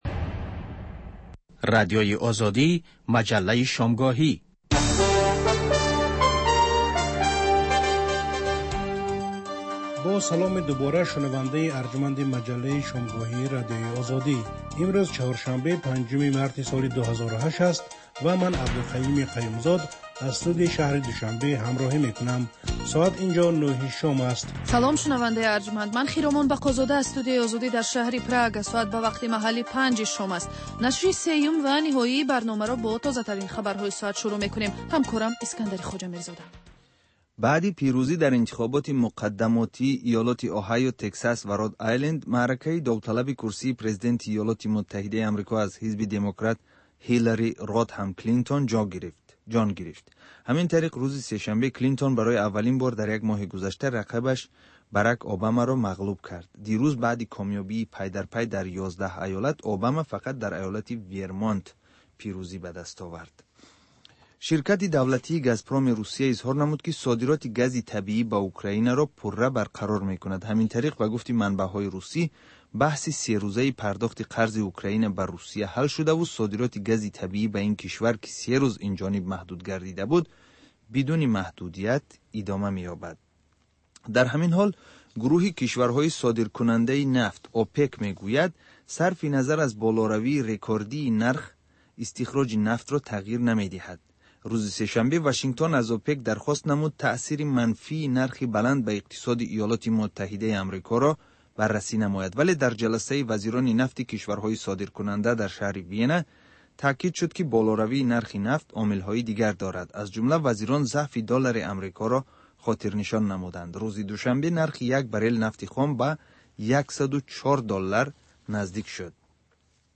Тозатарин ахбор ва гузоришҳои марбут ба Тоҷикистон, минтақа ва ҷаҳон дар маҷаллаи шомгоҳии Радиои Озодӣ